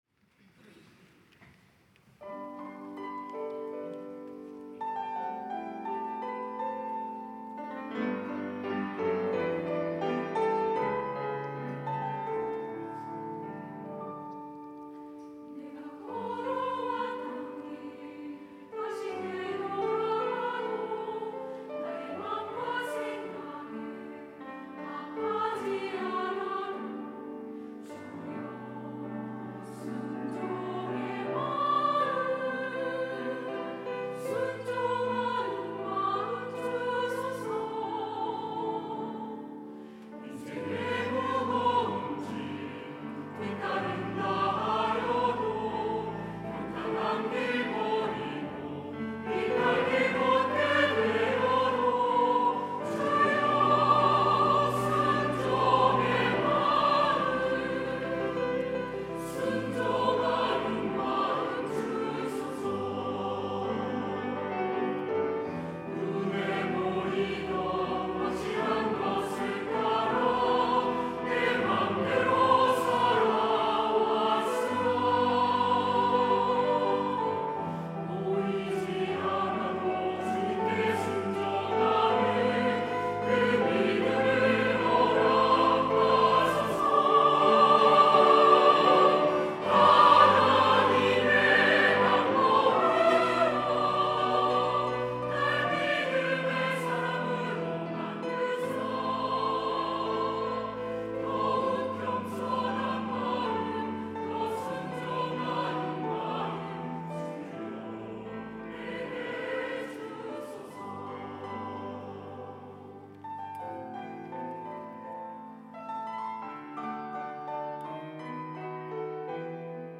할렐루야(주일2부) - 순종하는 마음
찬양대